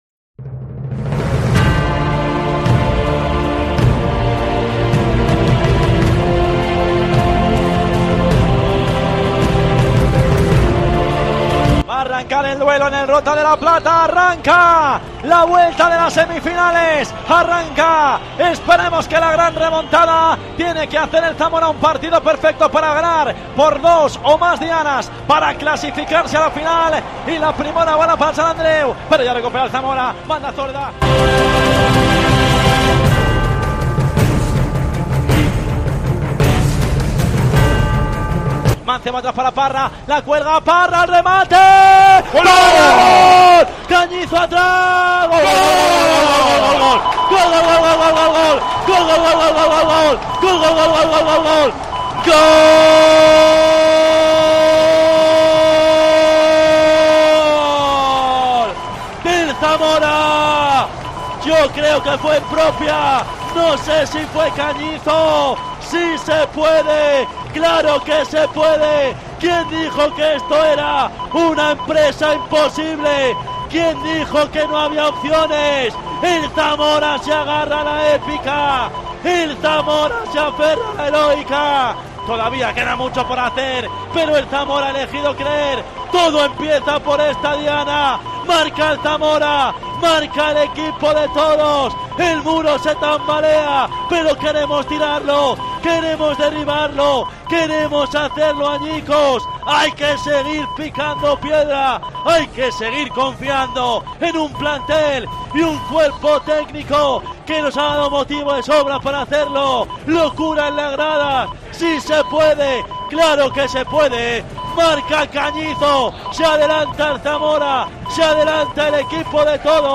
Así sonó en COPE Zamora la victoria del Zamora CF ante el Sant Andreu